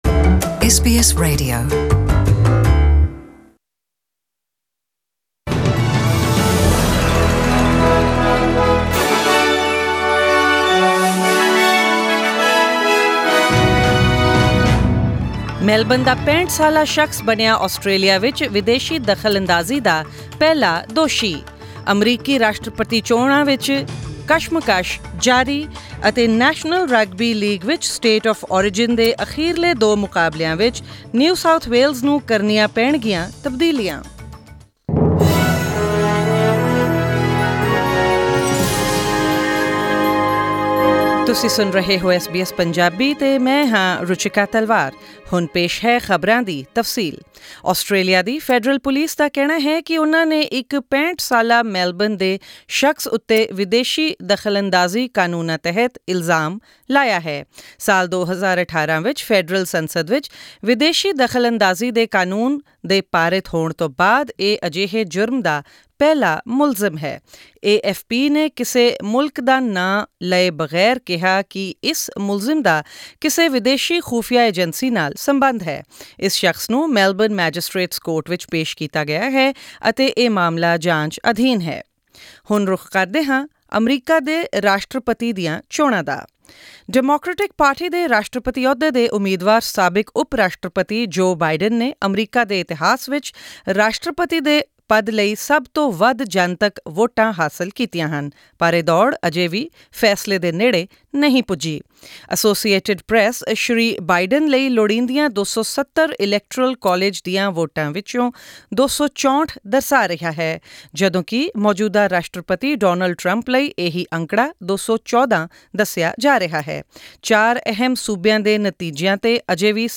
In this bulletin.....